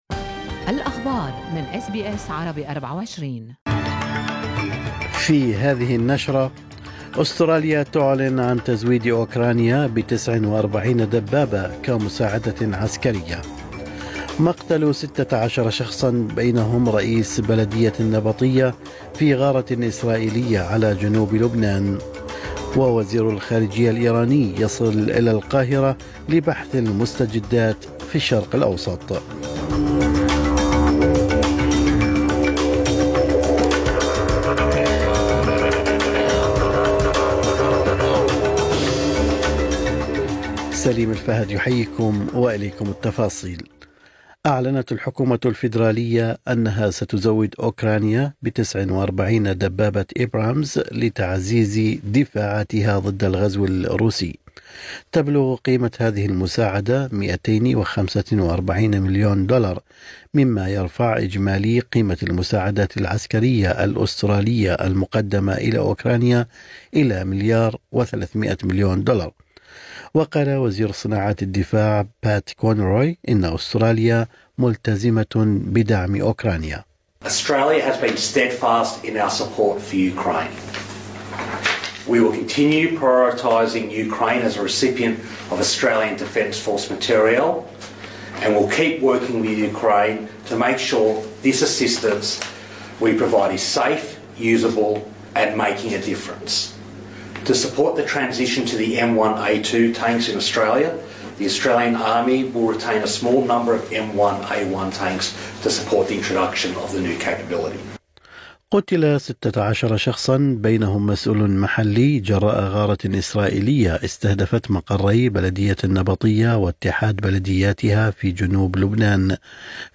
نشرة أخبار الصباح 17/10/2024